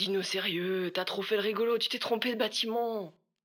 VO_LVL1_EVENT_Mauvais batiment_02.ogg